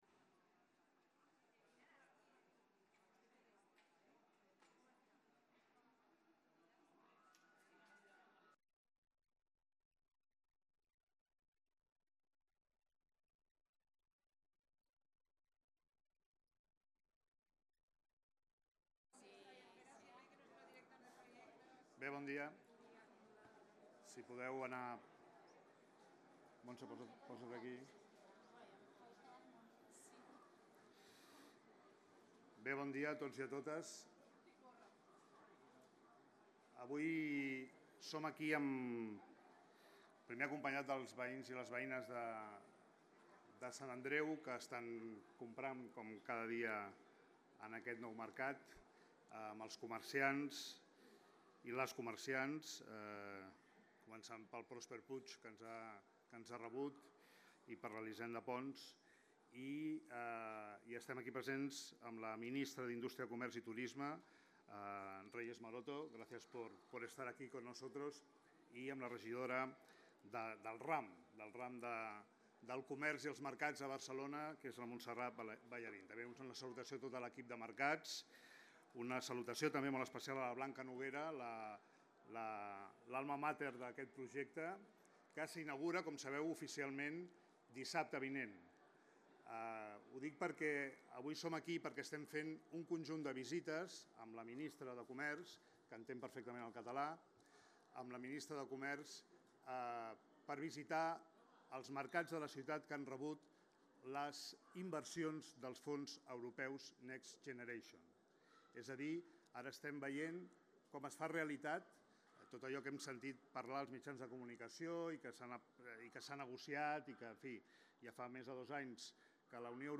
roda-de-premsa.mp3